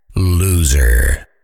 loser.ogg